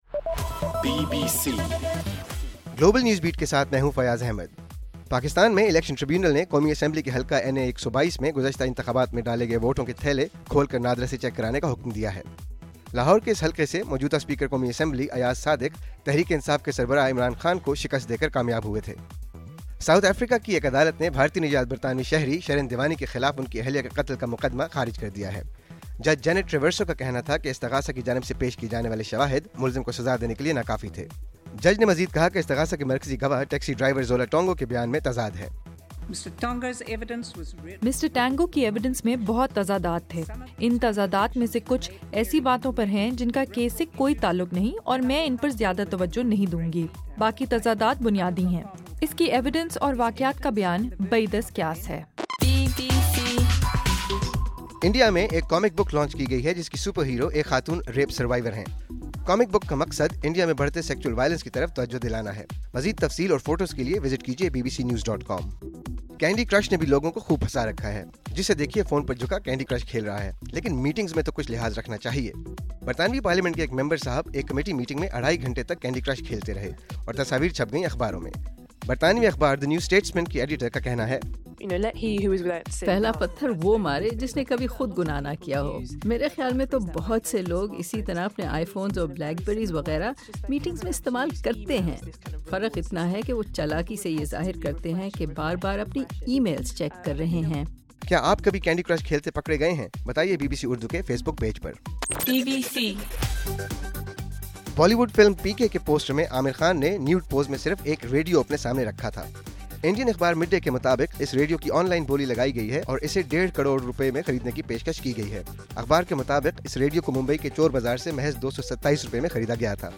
دسمبر 8: رات 9 بجے کا گلوبل نیوز بیٹ بُلیٹن